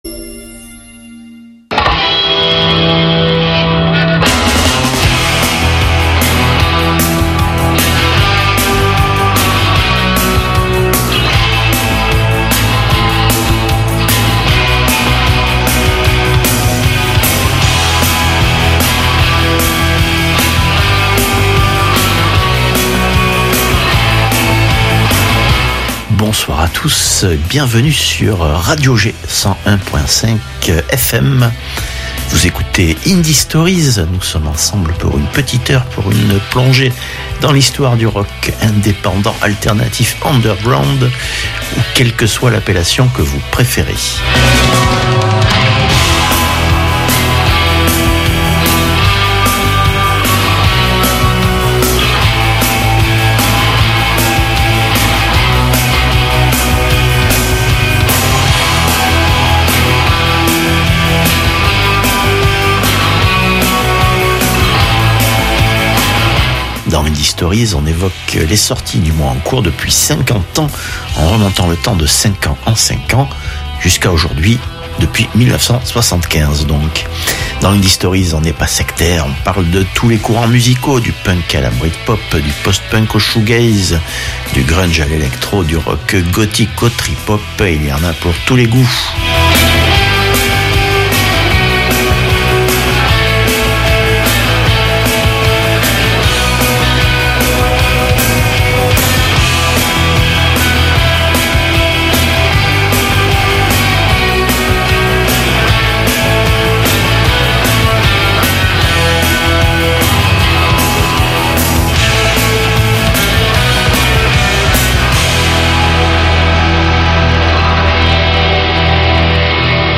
Et pour aller plus loin dans l'exploration de l'histoire du rock indé, c'est par ici !